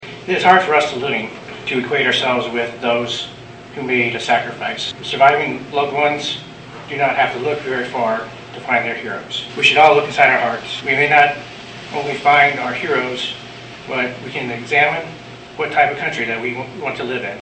The ceremony, which was moved indoors due to rain Monday, honored all the fallen soldiers from all of the wars throughout this country’s history.